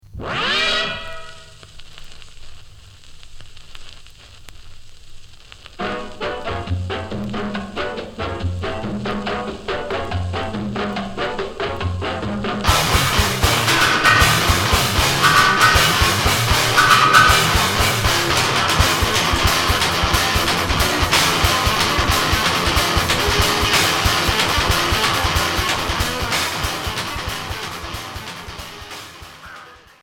Garage trash core